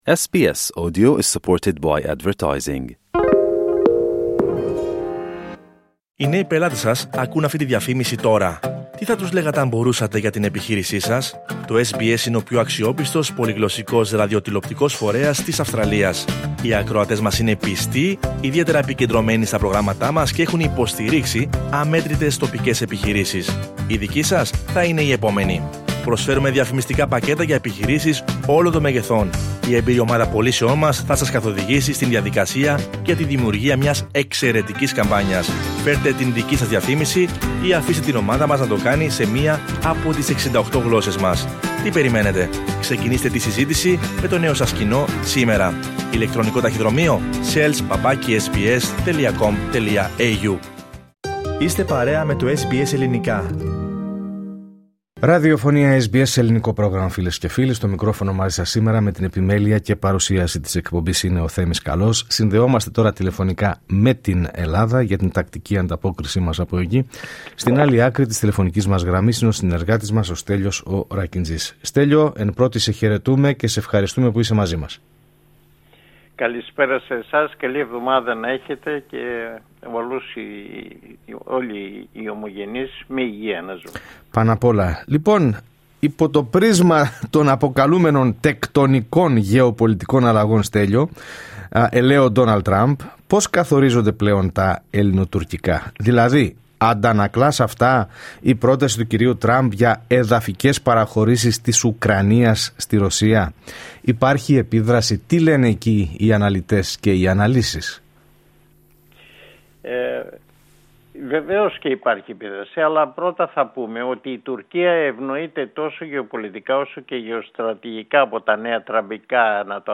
Ακούστε την εβδομαδιαία ανταπόκριση από Ελλάδα